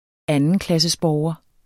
Udtale [ ˈanənʁɑŋs- ]